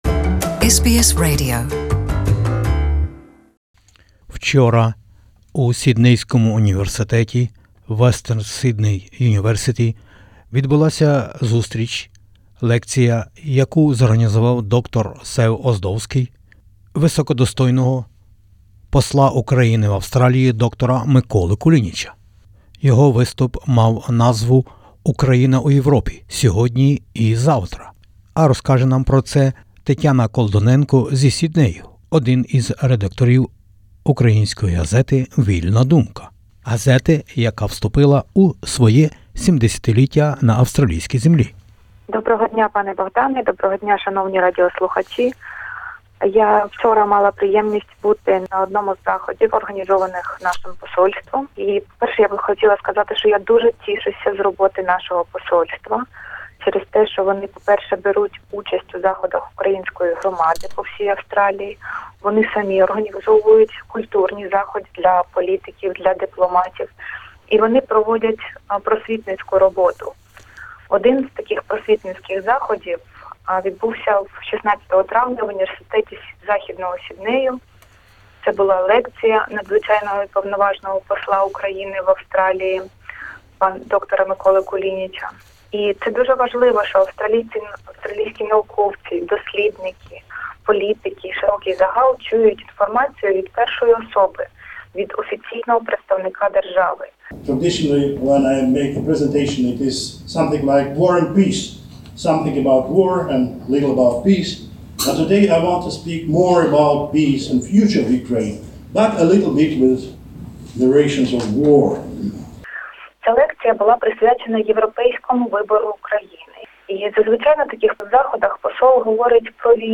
The Ambassador of Ukraine to Australia spoke about Ukraine in Europe. The fighting in eastern Ukraine's Donbas region is entering its fifth year.